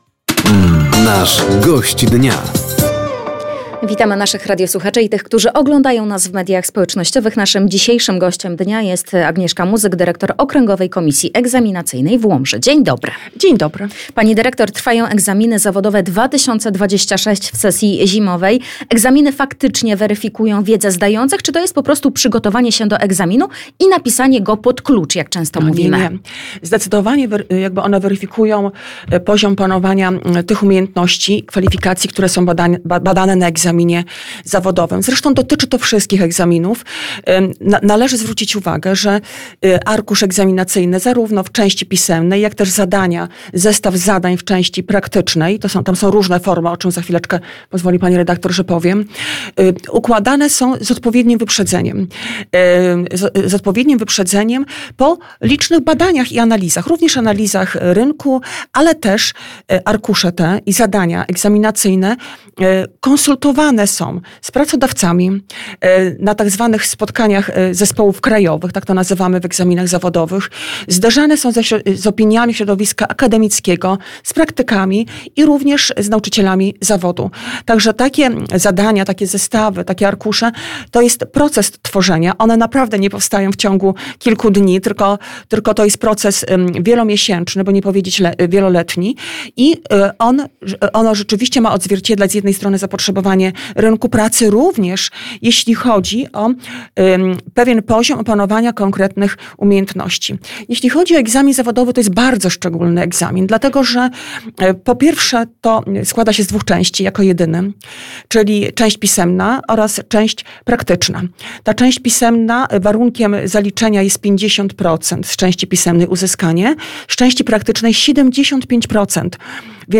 Zapraszamy do wysłuchania wtorkowej (13.01) audycji Gość Dnia Radia Nadzieja.